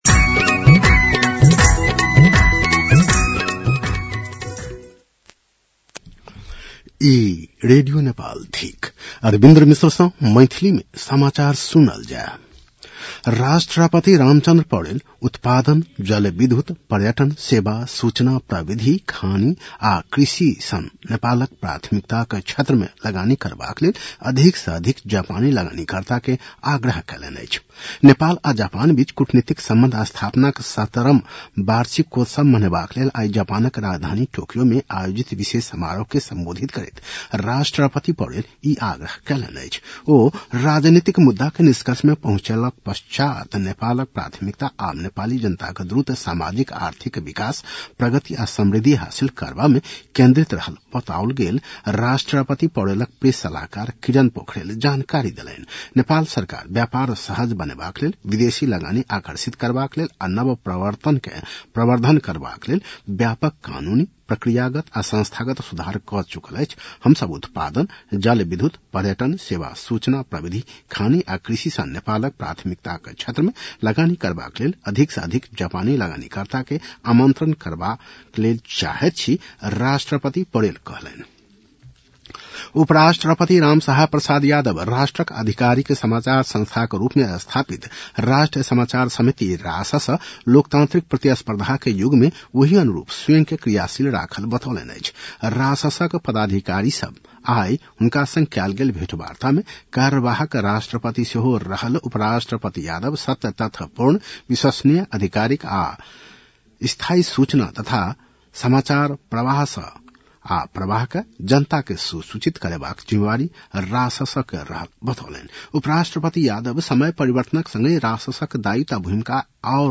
मैथिली भाषामा समाचार : १९ माघ , २०८२
6.-pm-maithali-news-.mp3